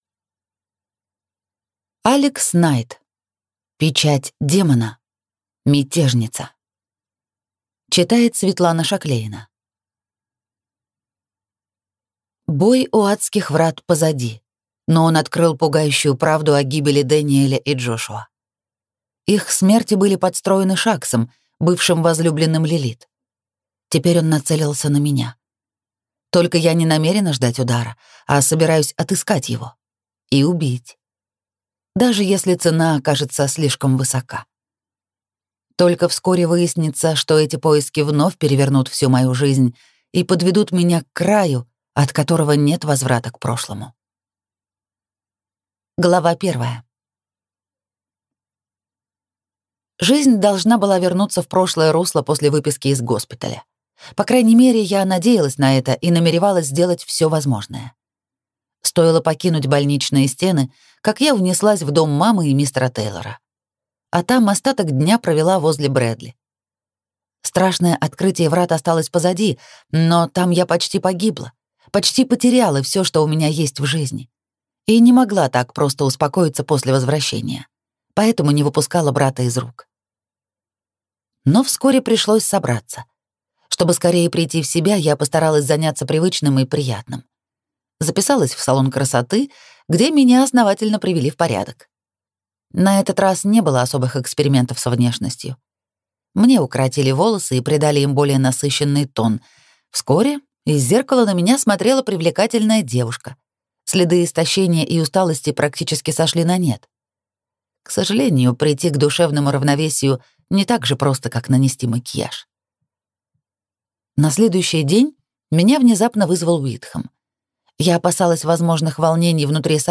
Аудиокнига Печать Демона. Мятежница | Библиотека аудиокниг
Прослушать и бесплатно скачать фрагмент аудиокниги